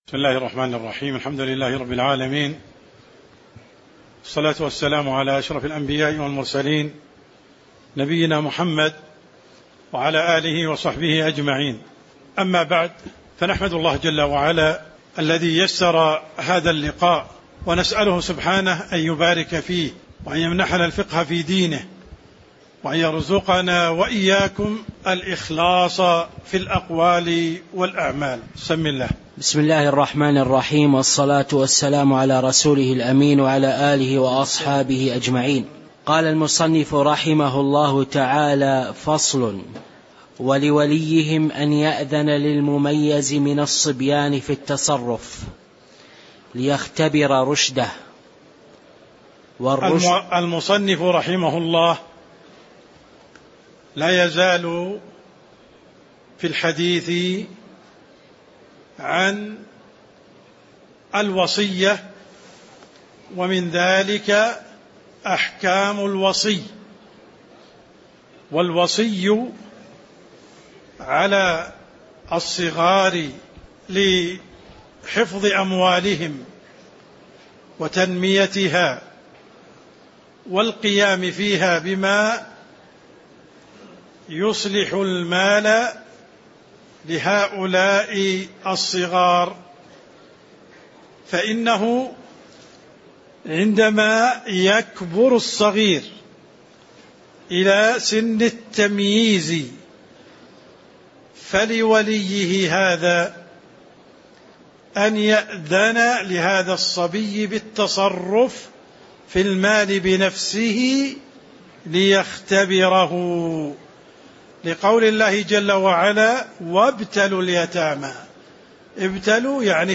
تاريخ النشر ٢٠ ربيع الأول ١٤٤٤ هـ المكان: المسجد النبوي الشيخ: عبدالرحمن السند عبدالرحمن السند قوله: فصل في الحجر واختيار الرشد (03)